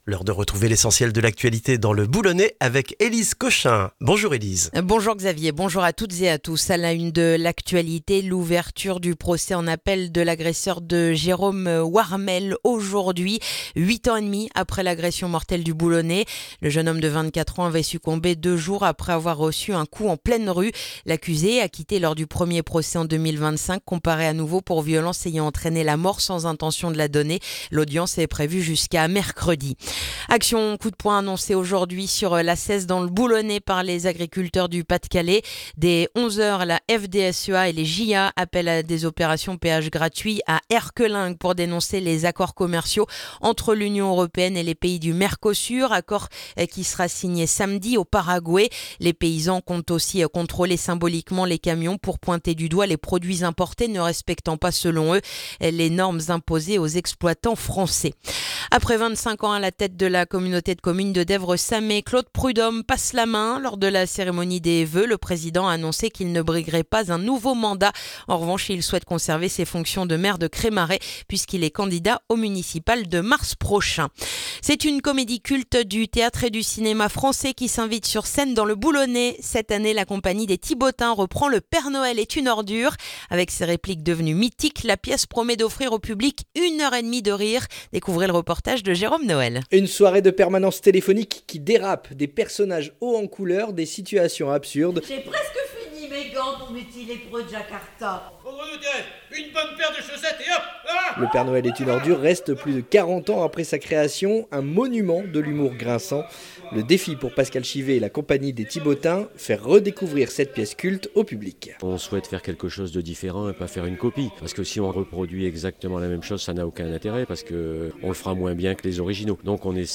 Le journal du lundi 12 janvier dans le boulonnais